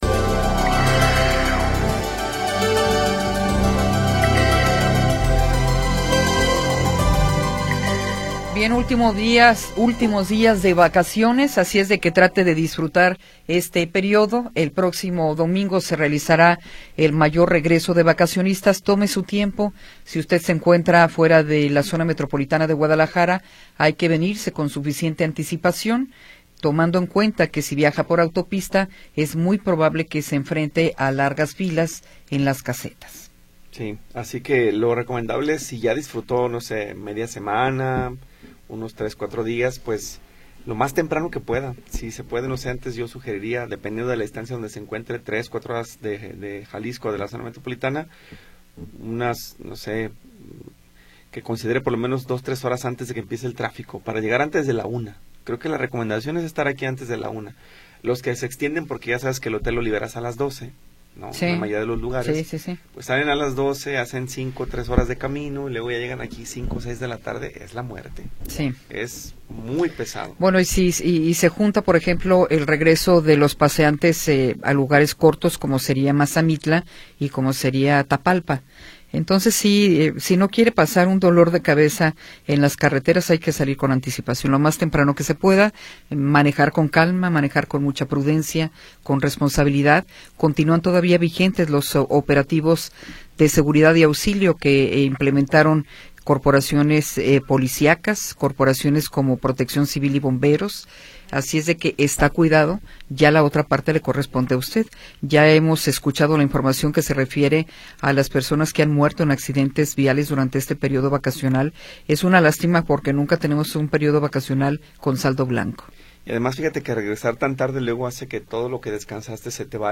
Información oportuna y entrevistas de interés
Tercera hora del programa transmitido el 9 de Abril de 2026.